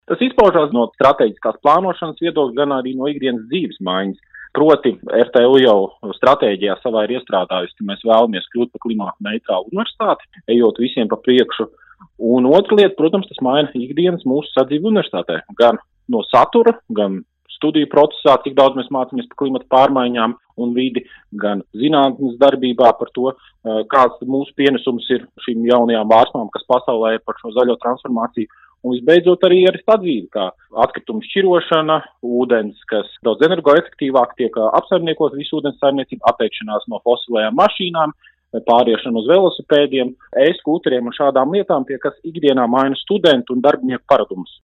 RADIO SKONTO Ziņās par RTU iegūto 50. vietu pasaules zaļāko universitāšu reitingā